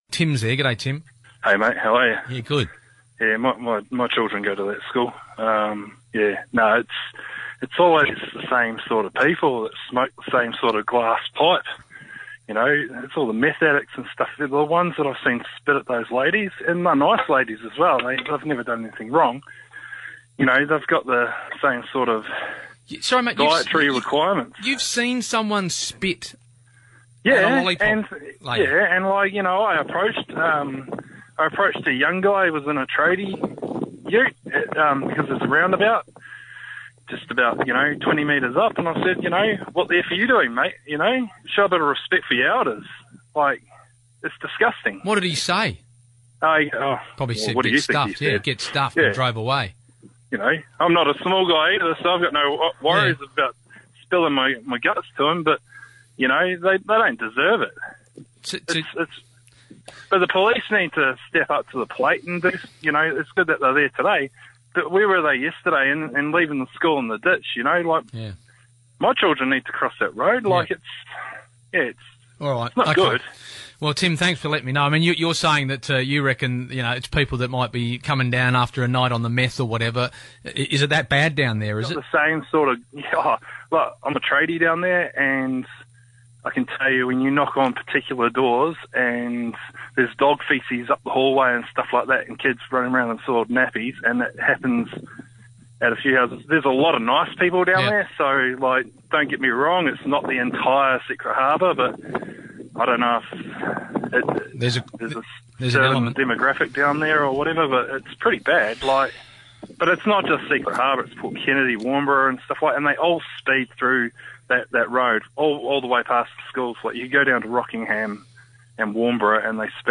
Talkback caller